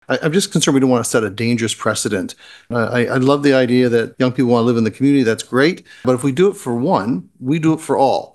Concerns noted in approving the application included setting an expectation that severances of this nature would be approved by council, which Mayor of South Huron George Finch was extremely apprehensive about.